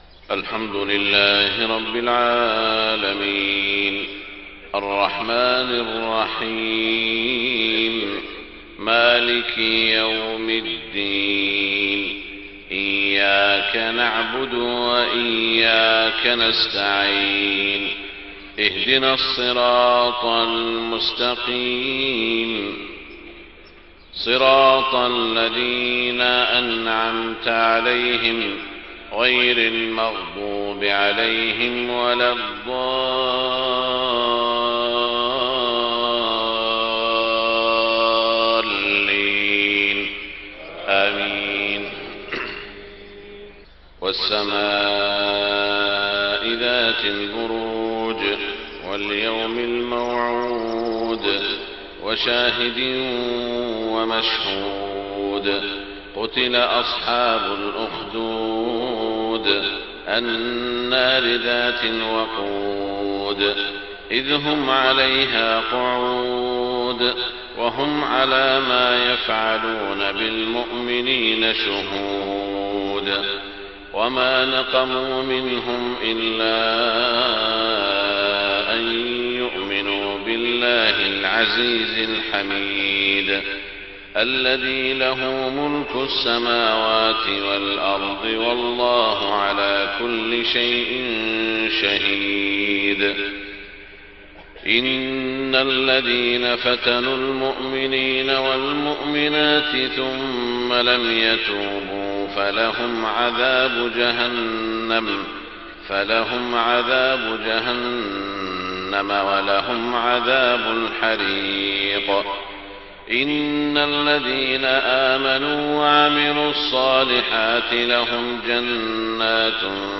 صلاة الفجر 3 محرم 1430هــ | سورتي البروج و الفجر > 1430 🕋 > الفروض - تلاوات الحرمين